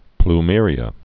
(pl-mîrē-ə)